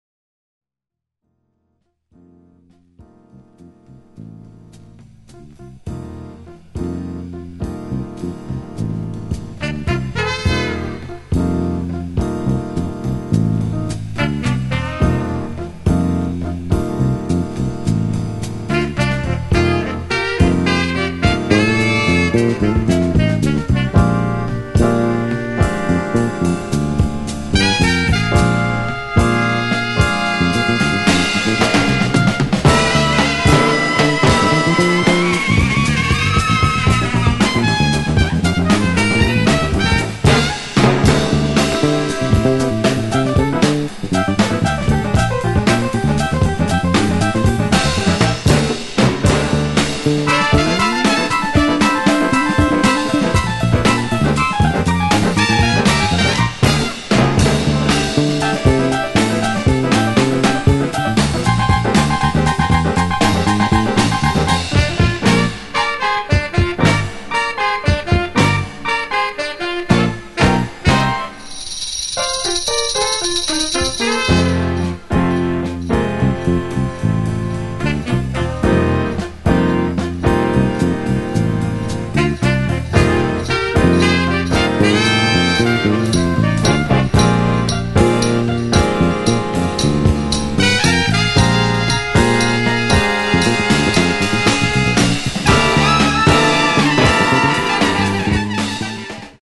spiritual jazz